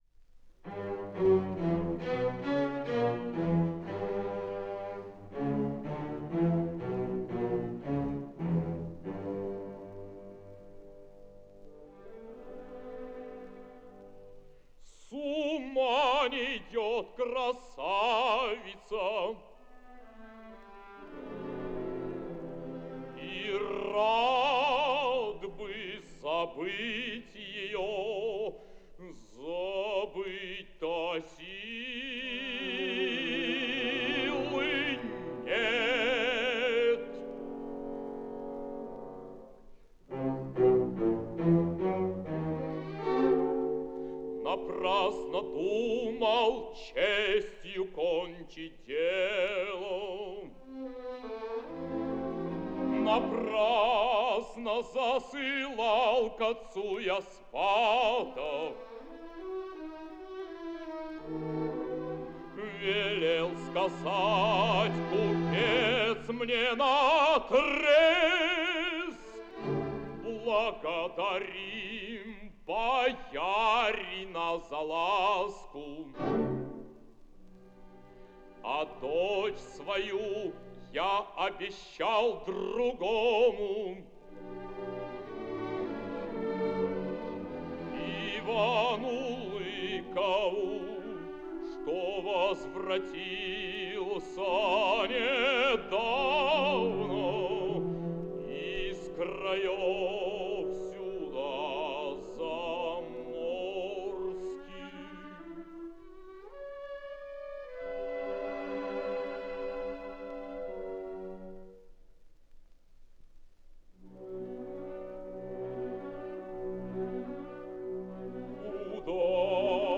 107 лет со дня рождения певца (баритон), солиста ГАБТ СССР, Народного артиста СССР, Алексея Петровича Иванова